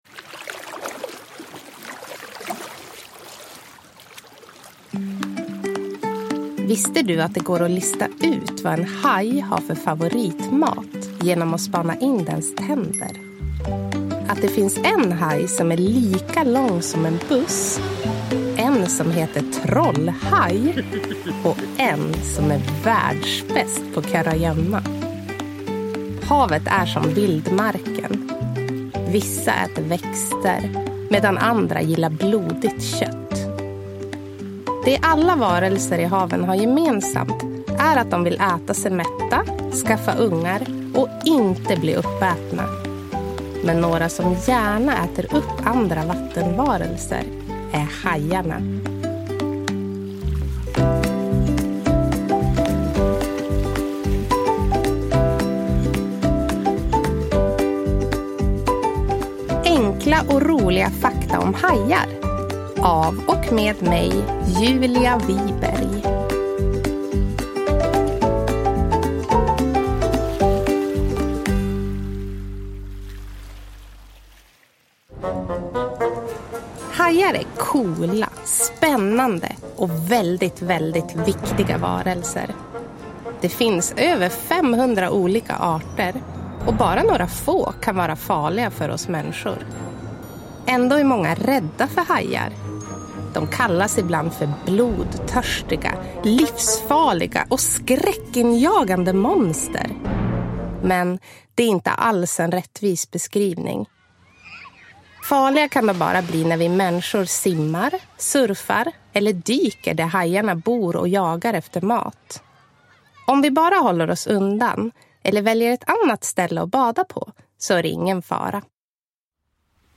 Enkla och roliga fakta om hajar – Ljudbok